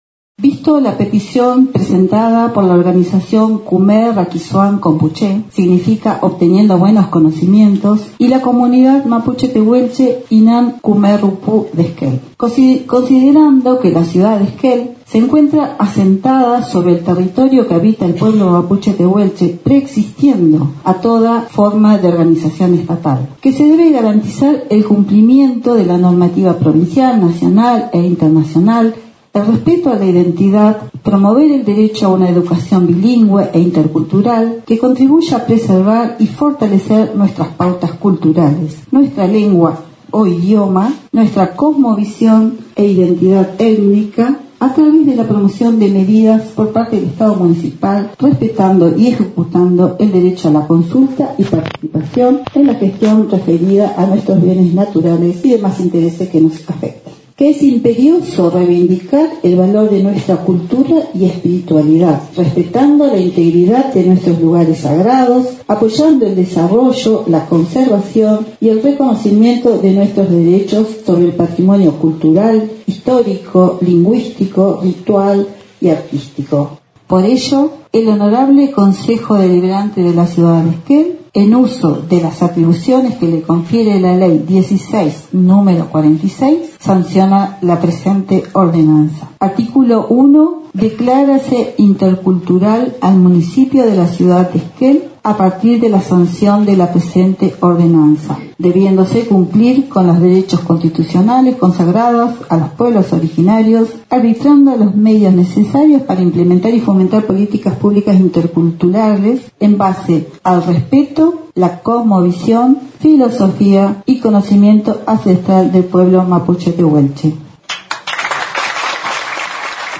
Durante la cuarta sesión ordinaria del período legislativo en el Concejo Deliberante, desde la organización del pueblo Mapuche Tehuelche Kume Rakizuam Compuche (obteniendo buenos conocimientos) y la Comunidad Mapuche Tehuelche Inam Küme Rupu de Esquel, presentaron un proyecto de ordenanza para que la ciudad de Esquel sea declarada municipio intercultural.